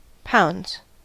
Ääntäminen
Ääntäminen US Haettu sana löytyi näillä lähdekielillä: englanti Käännöksiä ei löytynyt valitulle kohdekielelle. Pounds on sanan pound monikko.